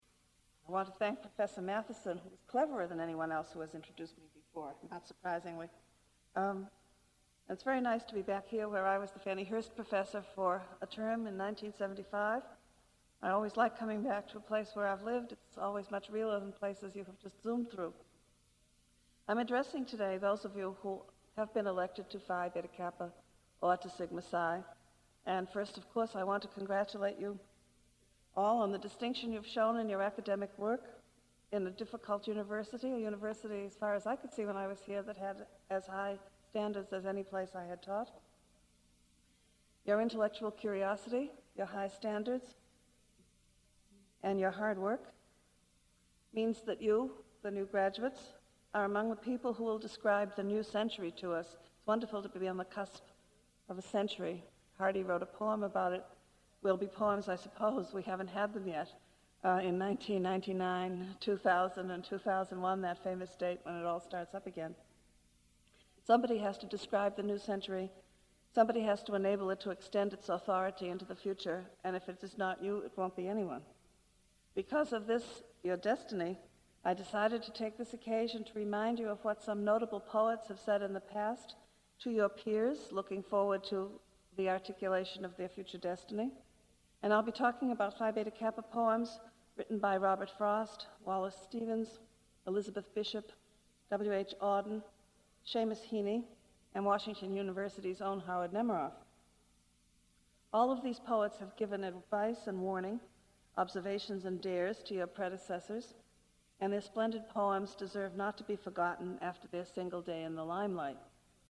Phi Beta Kappa Lecture